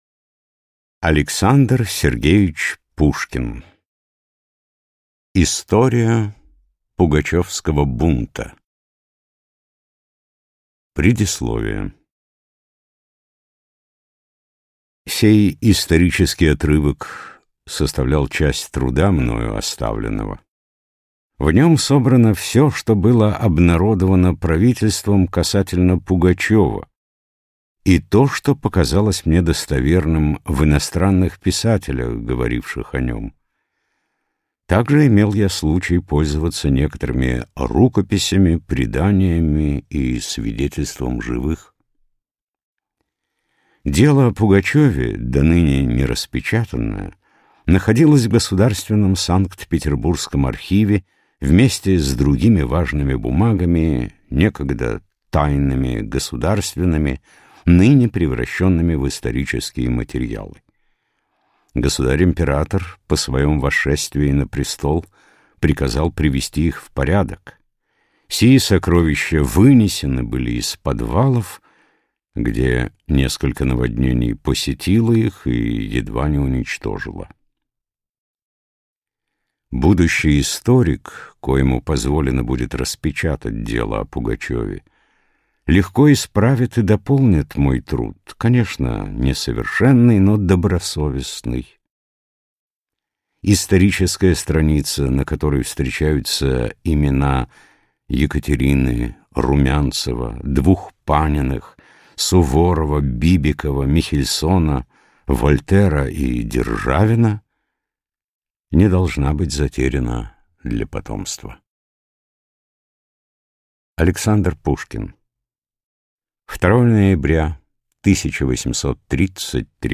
Аудиокнига История Пугачевского бунта | Библиотека аудиокниг